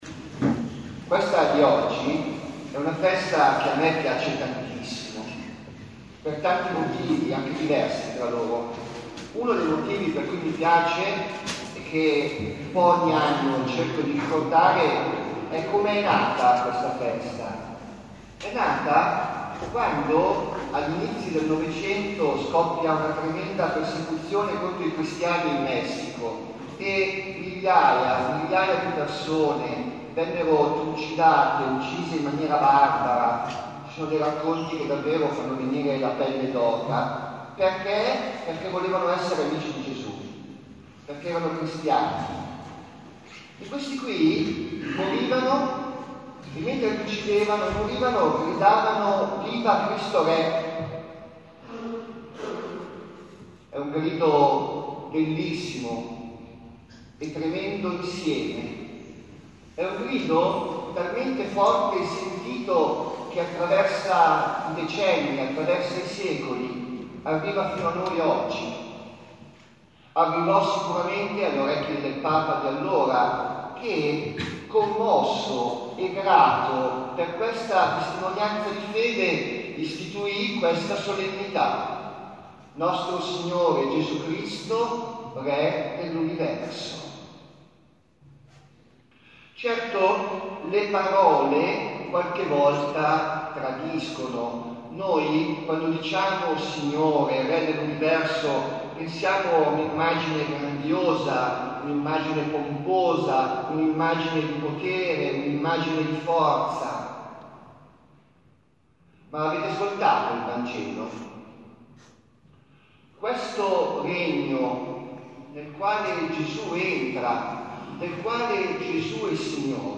Ascolta l'Omelia della celebrazione Eucaristica (Leggi le letture della Messa) in occasione della solennità di Cristo RE (.mp3)